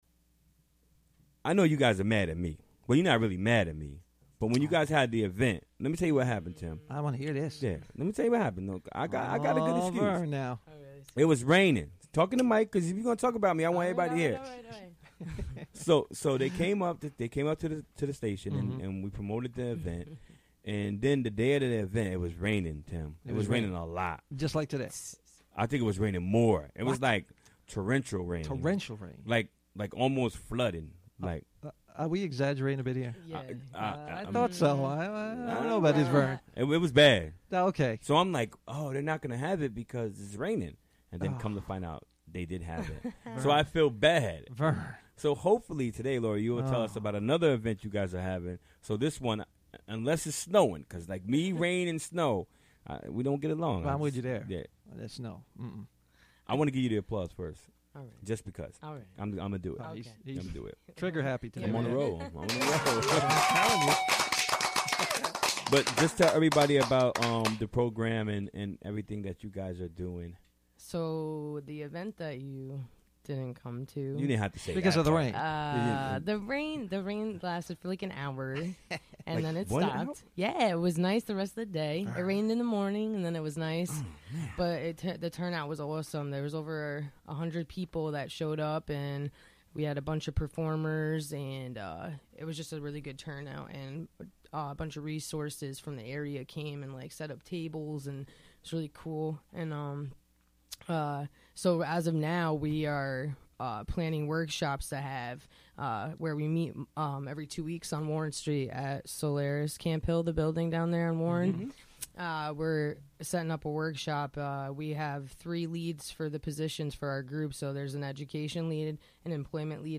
Recorded during the WGXC Afternoon Show Wednesday, November 30, 2016.